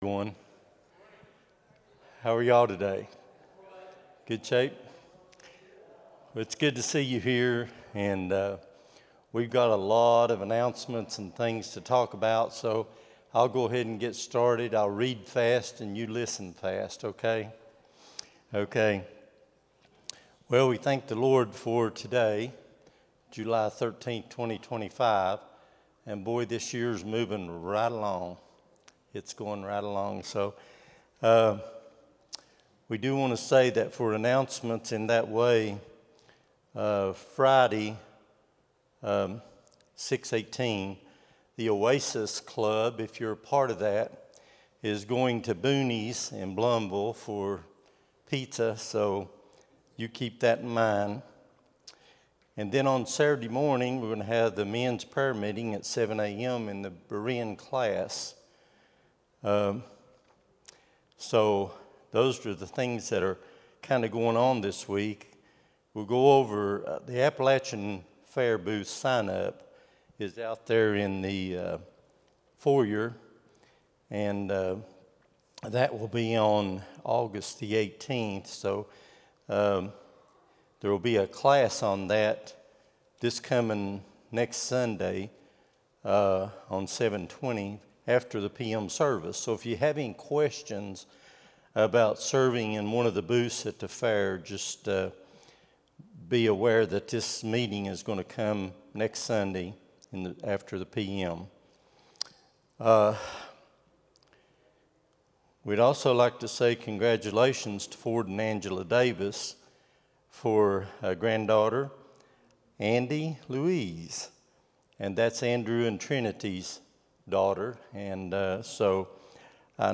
07-13-25 Sunday School | Buffalo Ridge Baptist Church